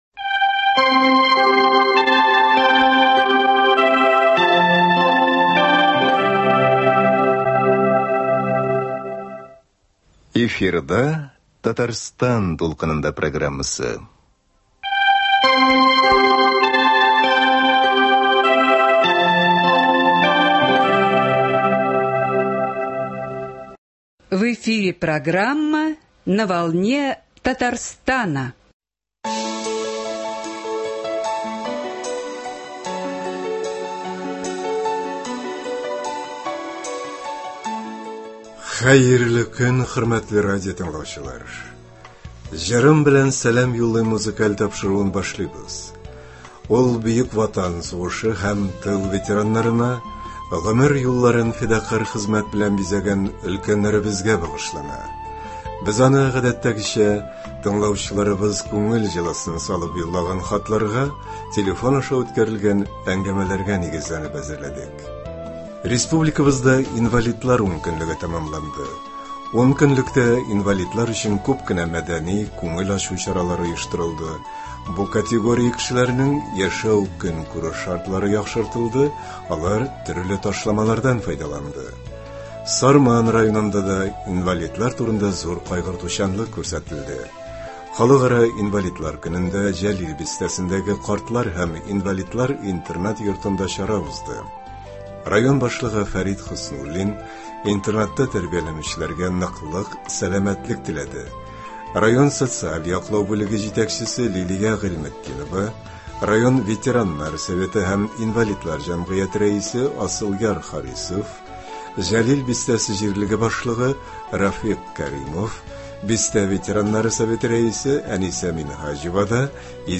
Ветераннар сораулары буенча музыкаль программа.